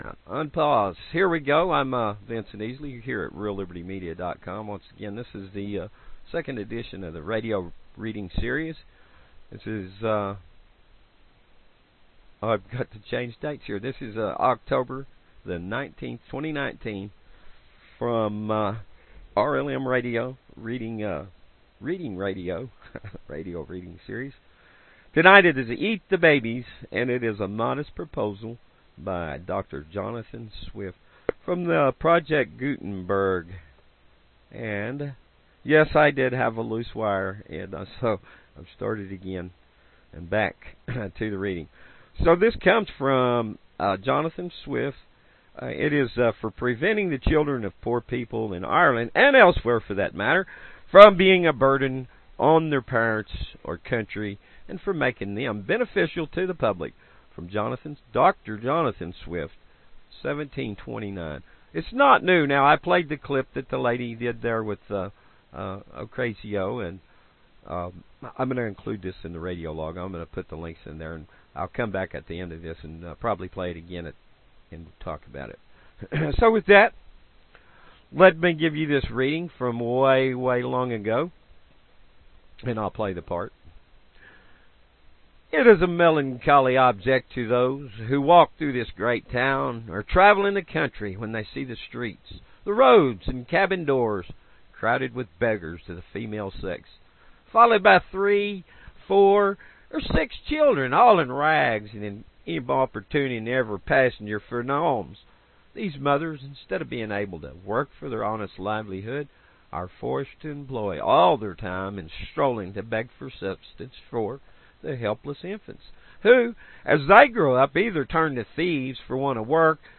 Genre Radio Reading